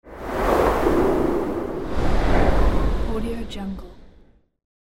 دانلود افکت صوتی اثر فشار هوا
Air Reveal Effect royalty free audio track is a great option for any project that requires transitions & movement and other aspects such as an air, reveal and dust.
Sample rate 16-Bit Stereo, 44.1 kHz